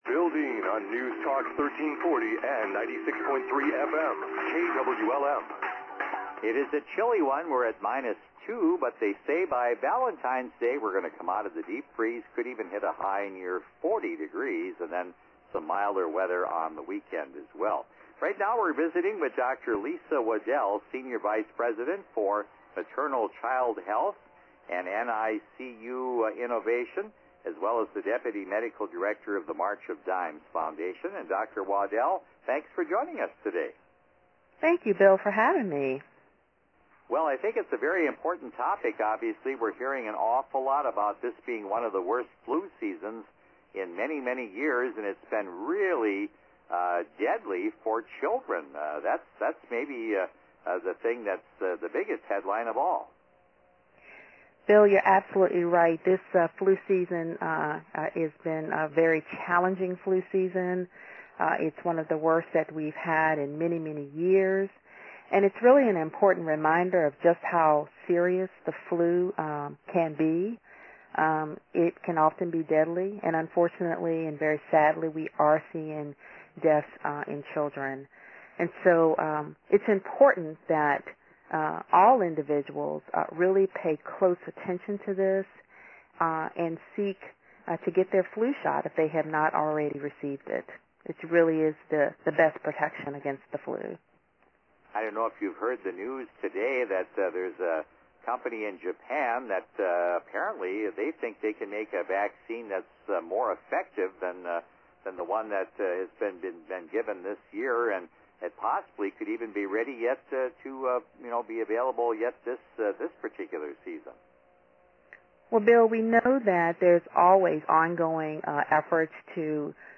Radio interviews:
8:50 am: seven minute live interview on KWLM-AM/FM (Wilmar, MN).